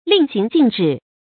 注音：ㄌㄧㄥˋ ㄒㄧㄥˊ ㄐㄧㄣˋ ㄓㄧˇ
令行禁止的讀法